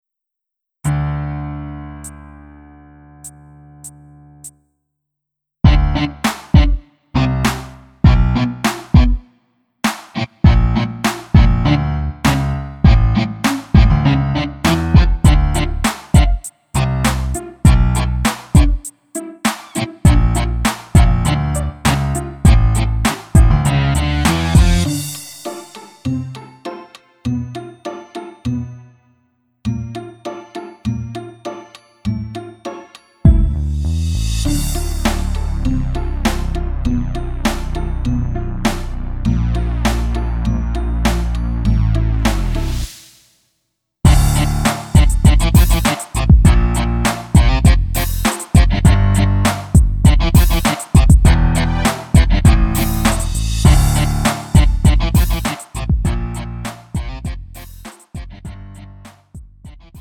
축가 및 결혼식에 최적화된 고품질 MR을 제공합니다!
음정 원키
장르 가요 구분 Lite MR